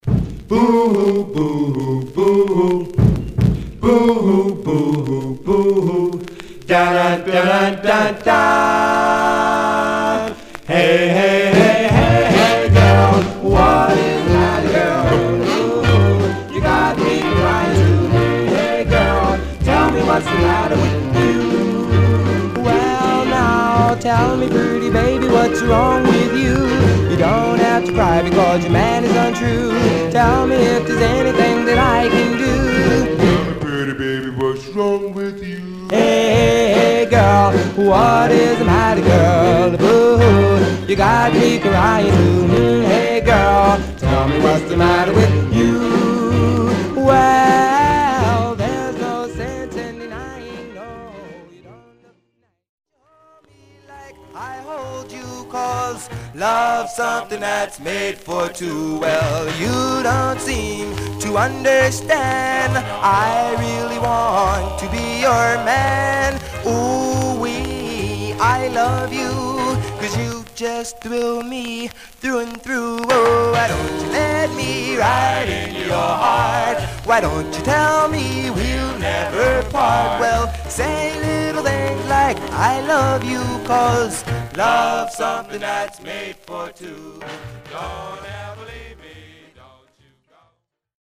Barely played, Some surface noise/wear Stereo/mono Mono
Male Black Groups